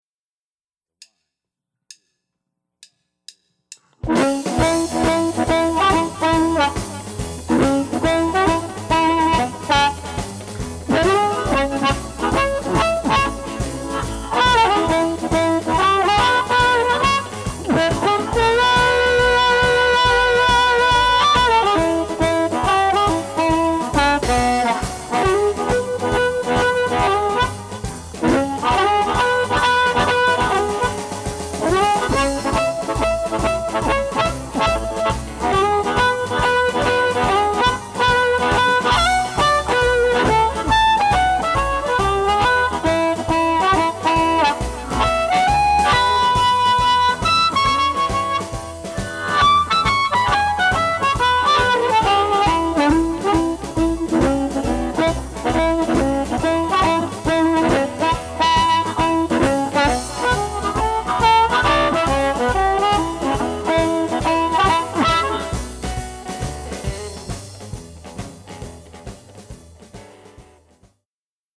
Amp Reviews | Blues Harmonica
Download the review sheet for each amp and the recorded mp3 files to compare the amps to make your choice of favorite amp.